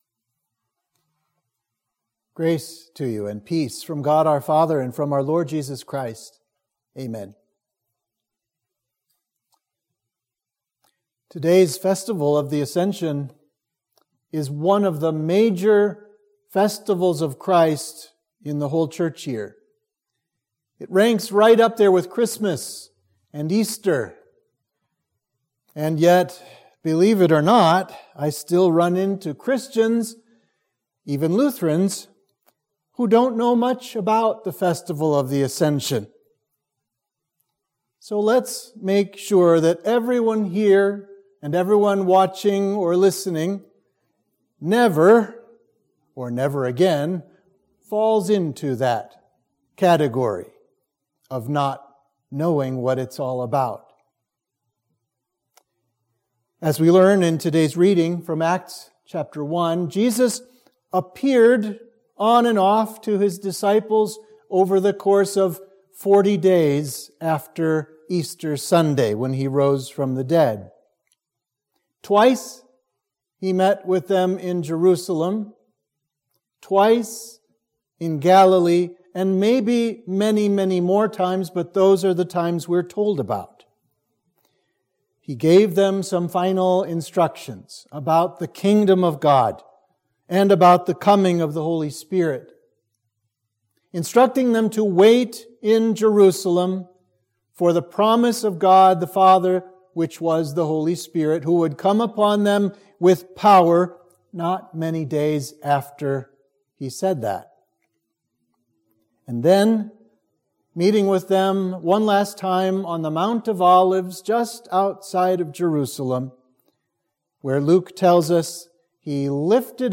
Sermon for the Festival of the Ascension